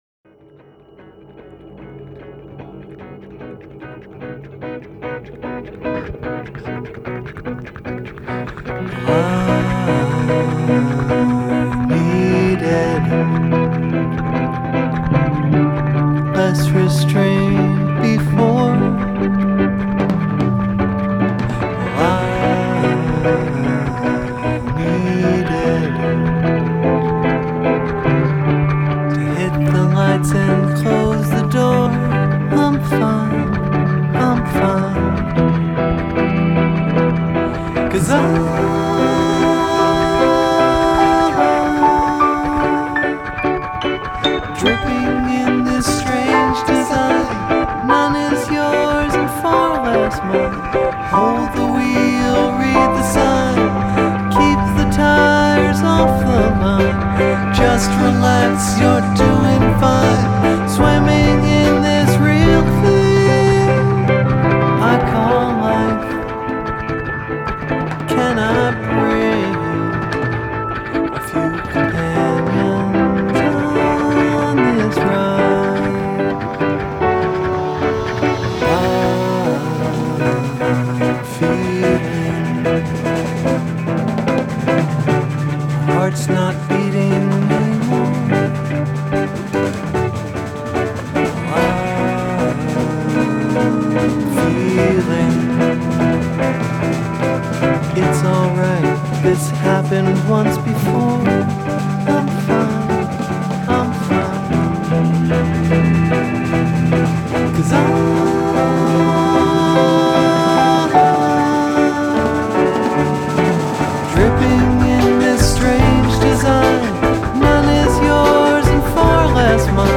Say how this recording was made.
Studio Version 1996: